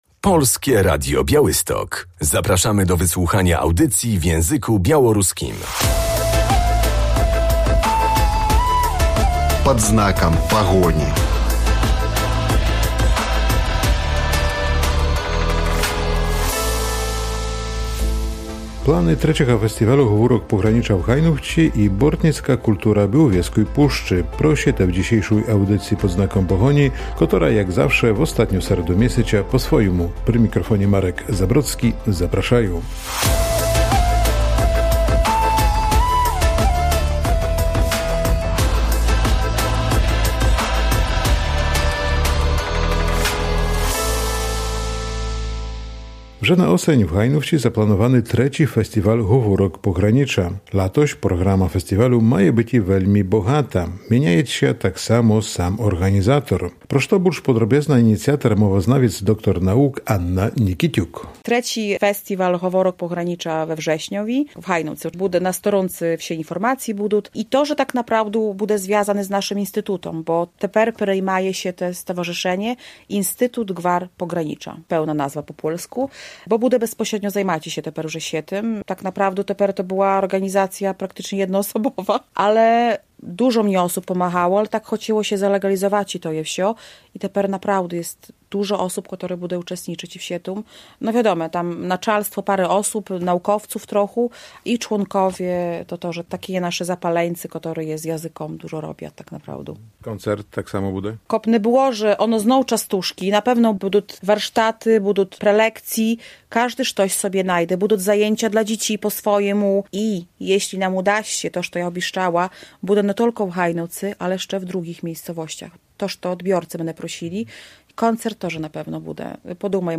Dziś audycja po – svojomu.